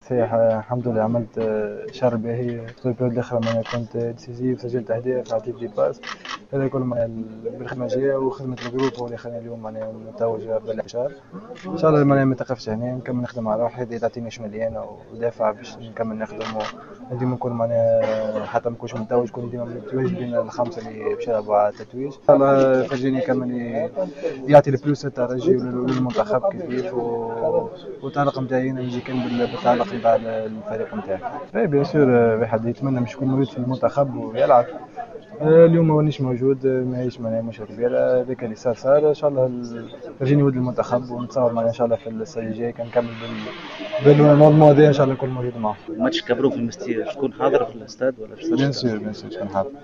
و أكد ساسي في تصريح لجوهرة أف أم أنه سعيد جدا بهذا التتويج الذي لم يأتي من فراغ خاصة أنه ثمرة المجهودات التي قدمها مع فريقه طيلة مشوار البطولة .